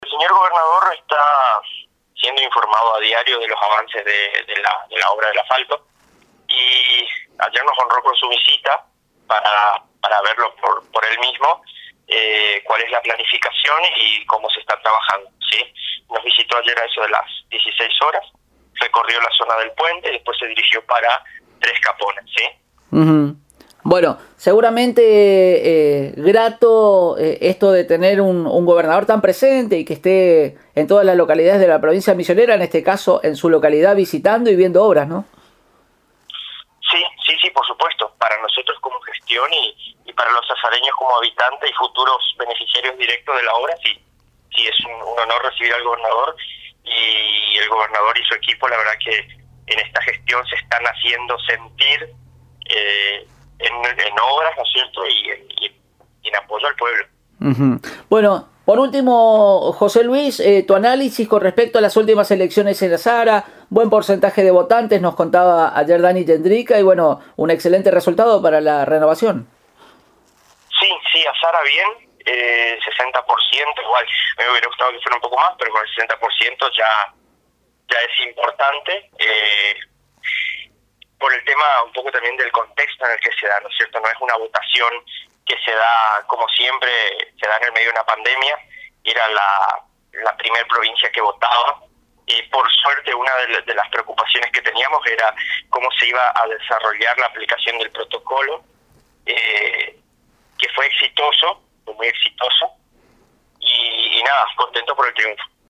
En charla con Radio Elemental […]